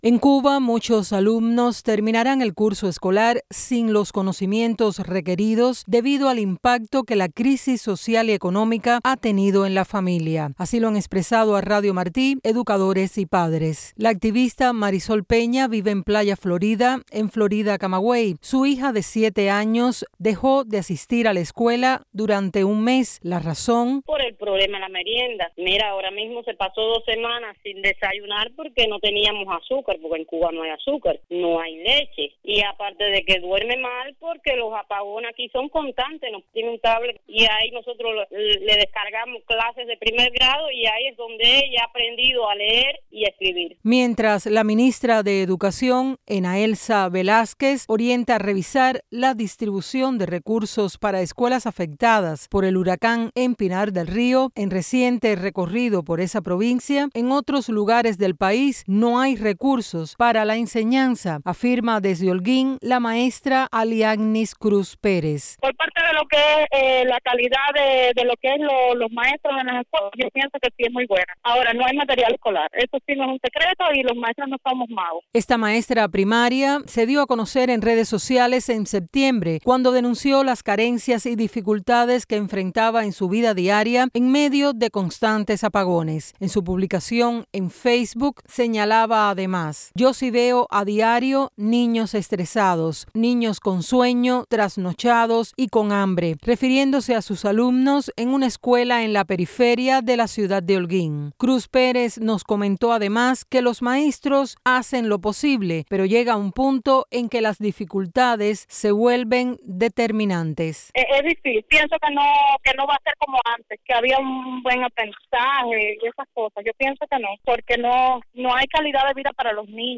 En Cuba, muchos alumnos terminarán el curso escolar sin los conocimientos requeridos debido al impacto que la crisis social y económica ha tenido en las familias, dijeron educadores y padres este jueves a Radio Martí.